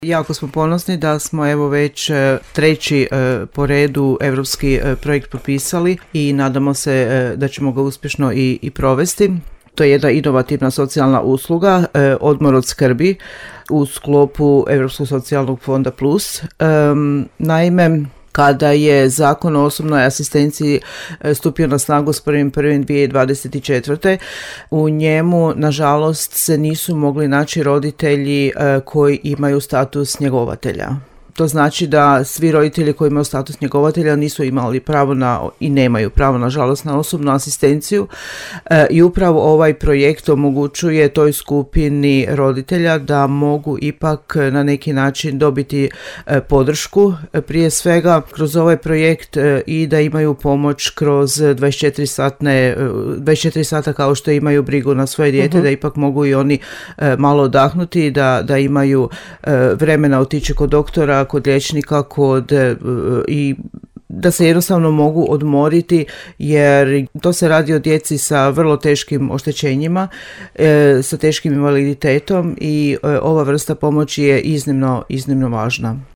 u emisiji Tiha snaga u programu Podravskog radija